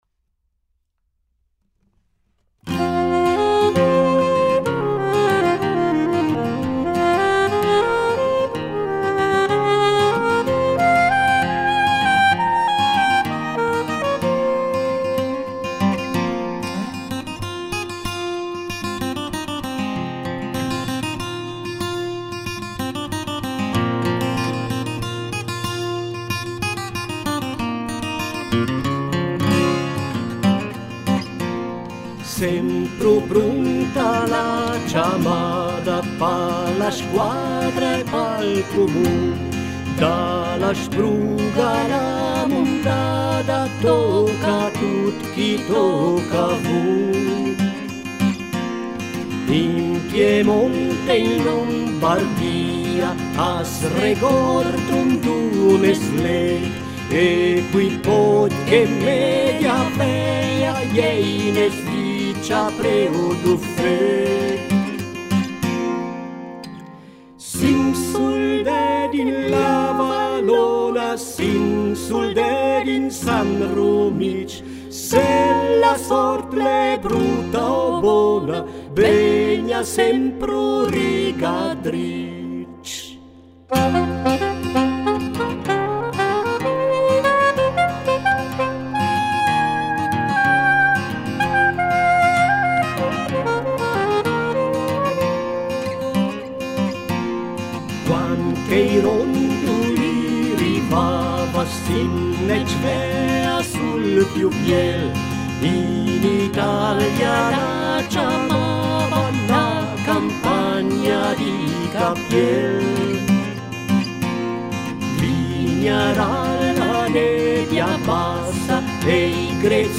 Historic folk music from Ticino.